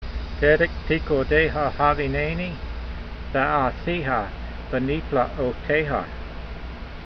v27_voice.mp3